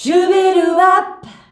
SHUBIWAP G.wav